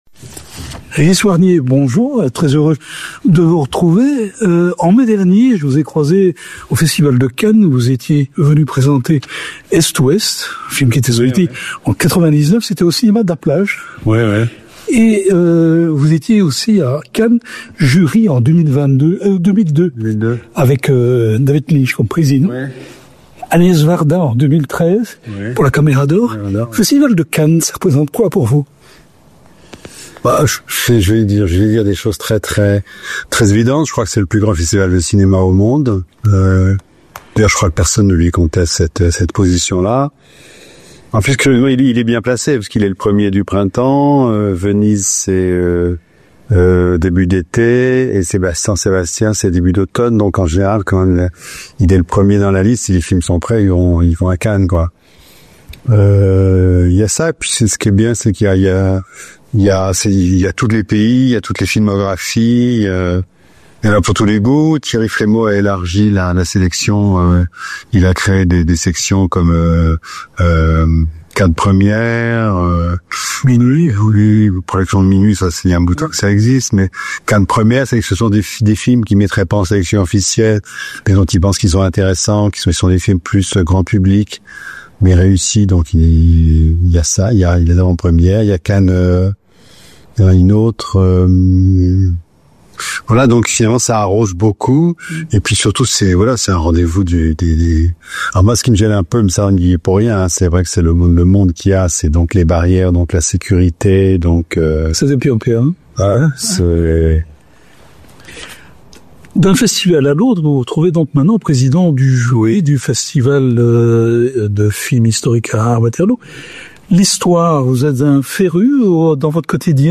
Il était l’homme idéal pour présider la 13e édition du Festival du Film Historique de Waterloo. Rencontre.